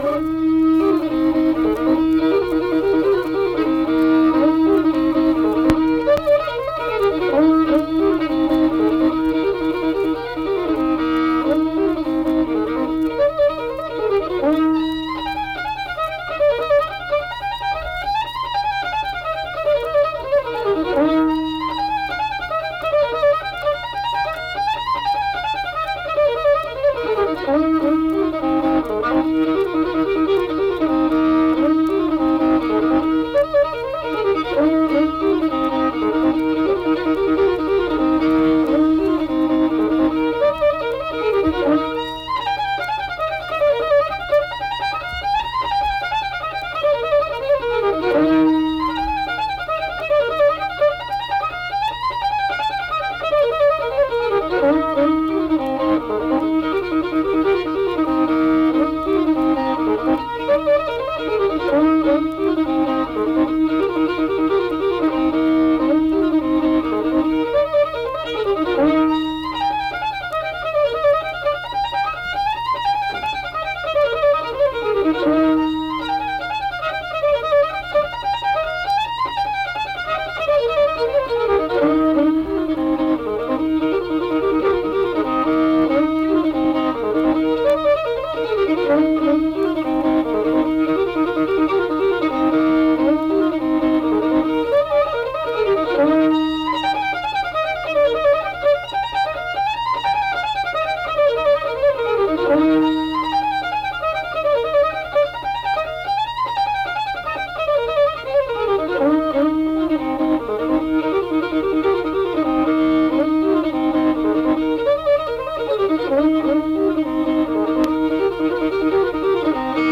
Unaccompanied fiddle music and accompanied (guitar) vocal music
Instrumental Music
Fiddle